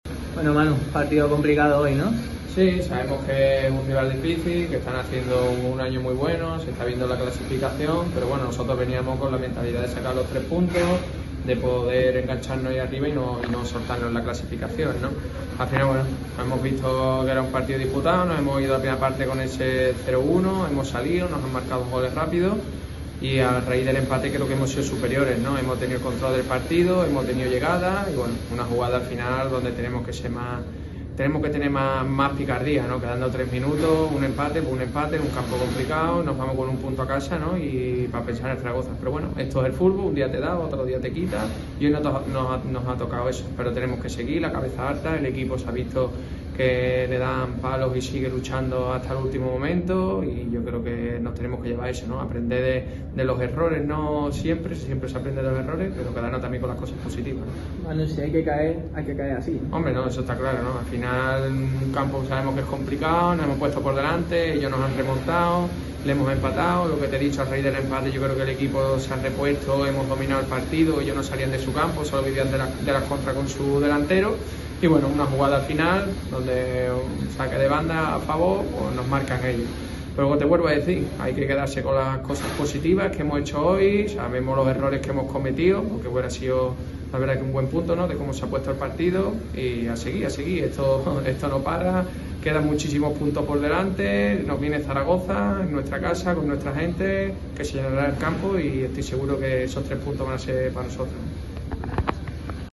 “Si se tiene que perder que sea así”, analizan los goleadores en zona mixta.